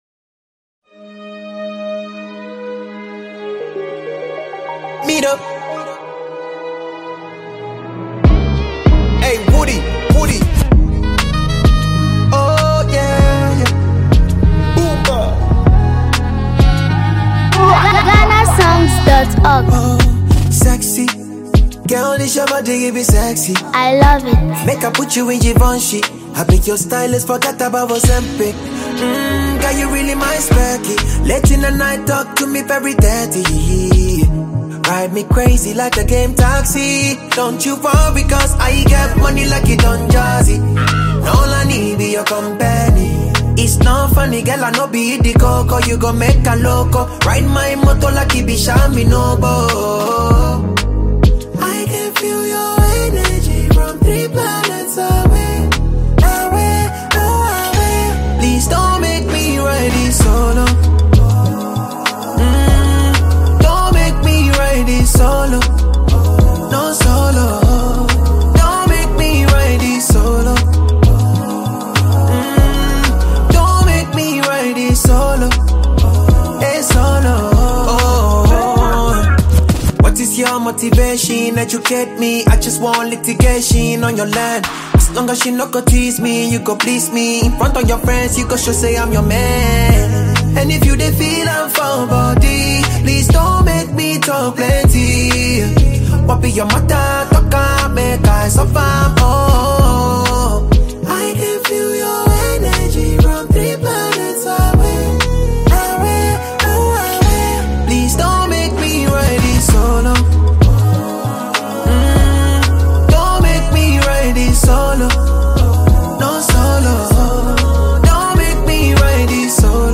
Known for his soulful voice and heartfelt lyrics
With a calm Afrobeat instrumental and touching vocals